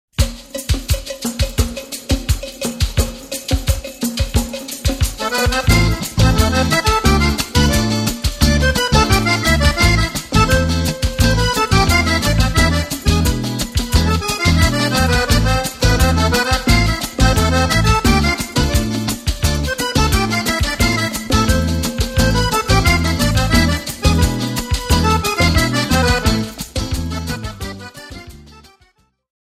Ballabili Sudamericani
Samba